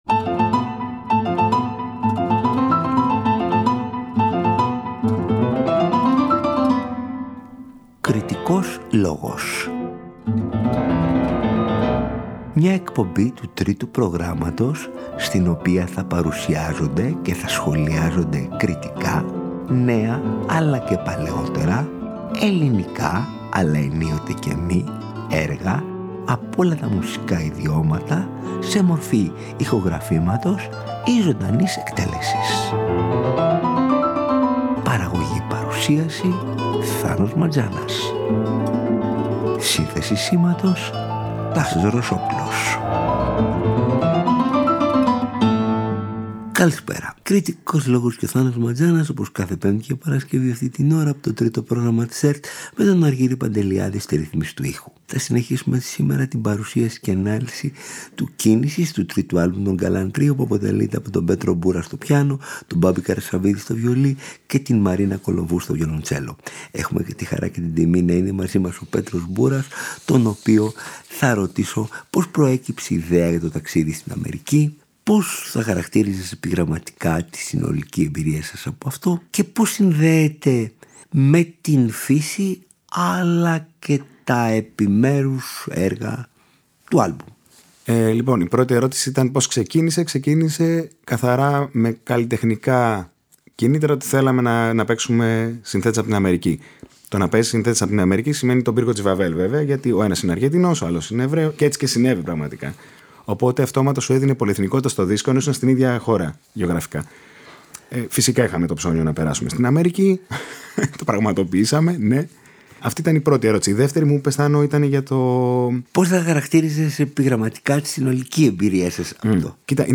ένα σύνολο μουσικής δωματίου, ένα πιάνο τρίο
στο πιάνο
στο βιολί
στο βιολοντσέλο